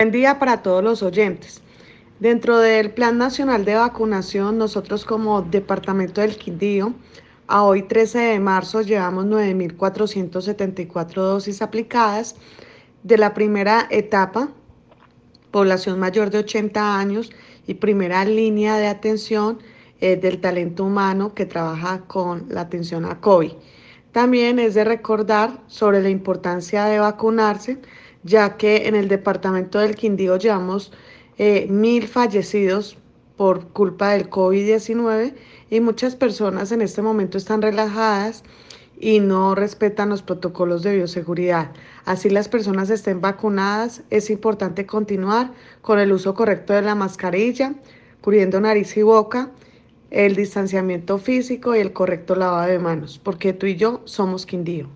Audio: Yenny Alexandra Trujillo, secretaria de Salud departamental